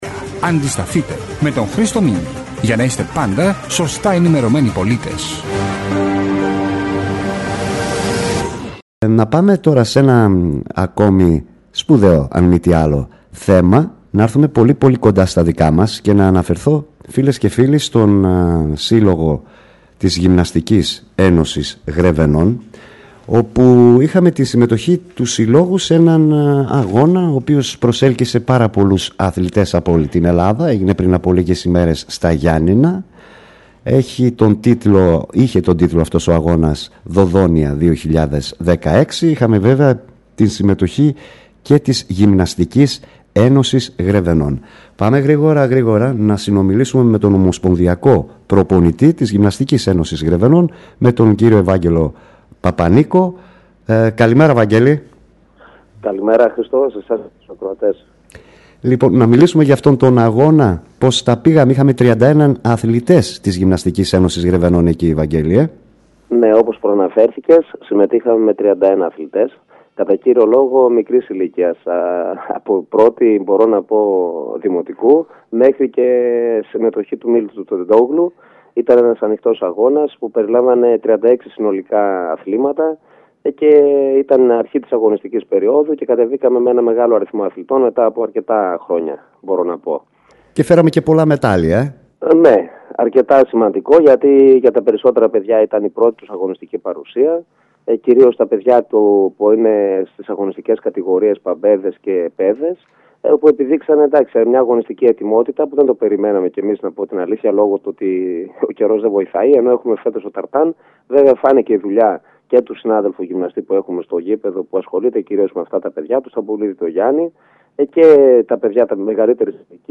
ΑΘΛΗΤΙΚΑ ΣΤΙΒΟΣ ΣΥΝΕΝΤΕΥΞΗ